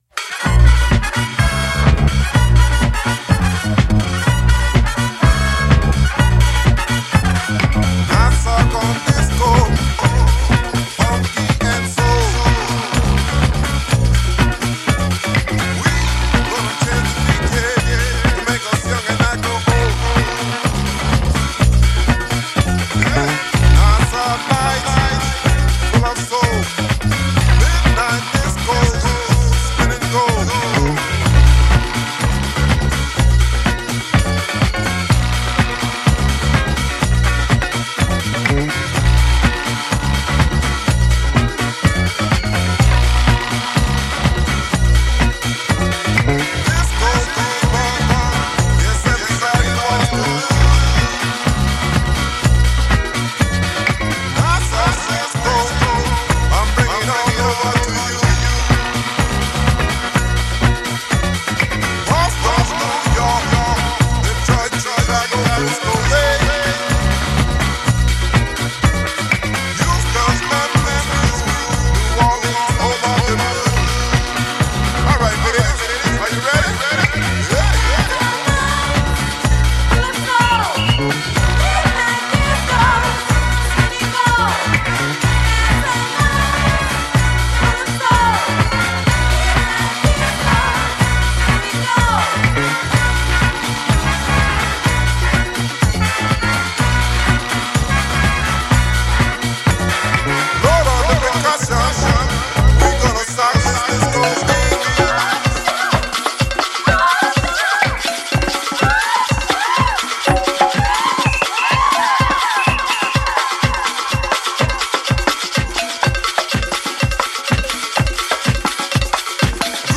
reggae disco masterpiece
devastating disco take
offering a loud and remastered 12” single for all.